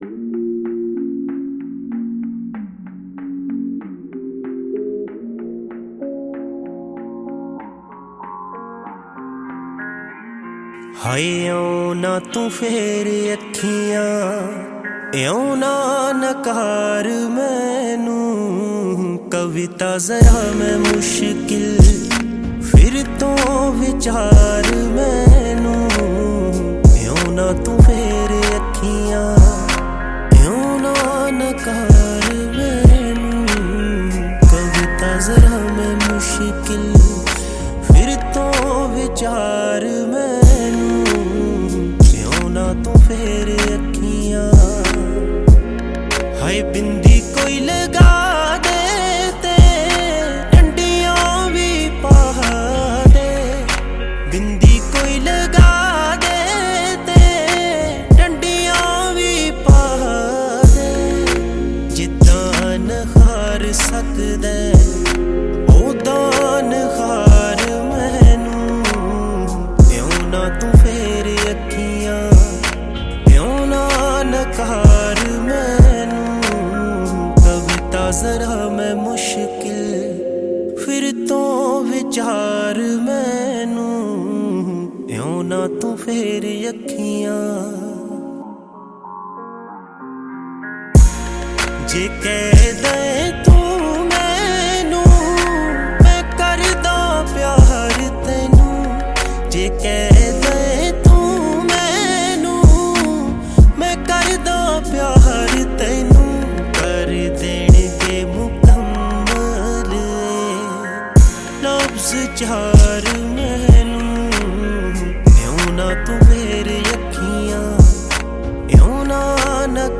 sad song